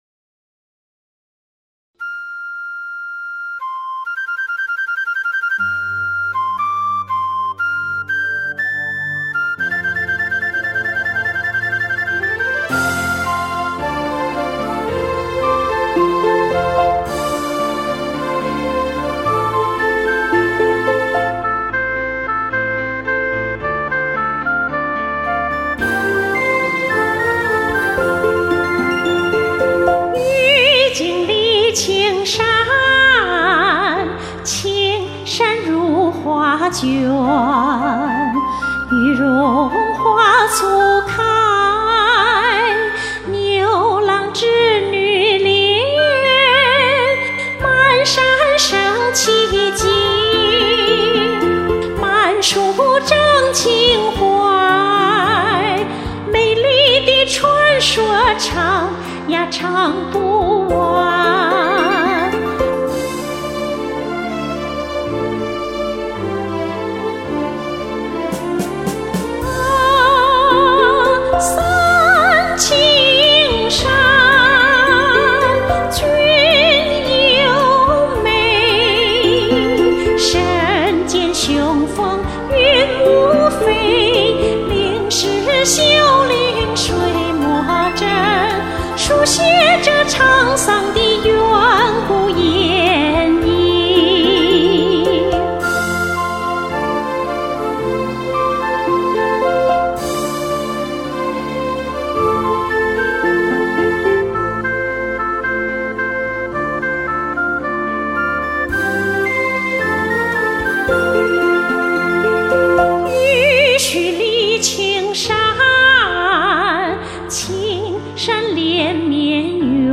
原创歌曲【情满三清山